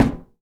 metal_tin_impacts_soft_05.wav